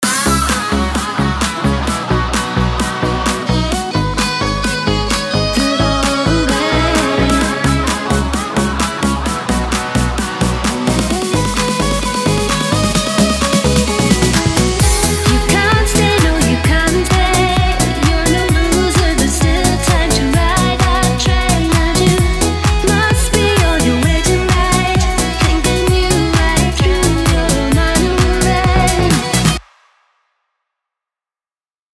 Irish dance remix extended és radio edit